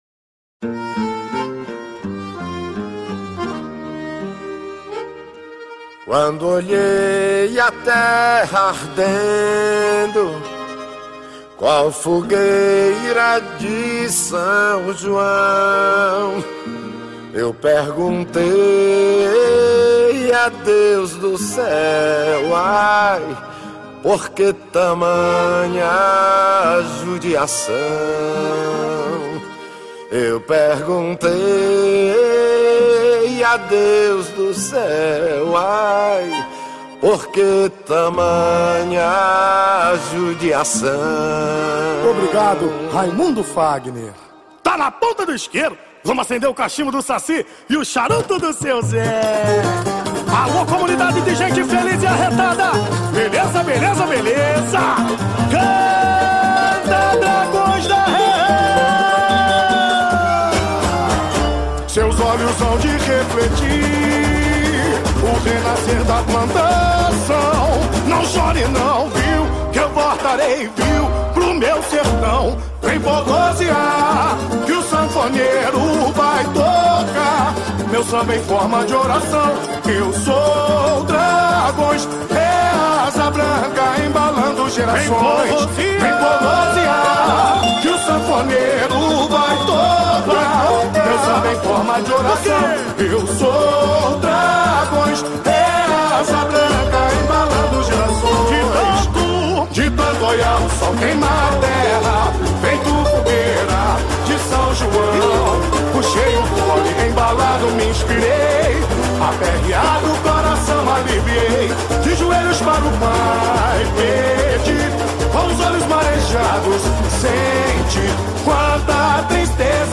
Interprete: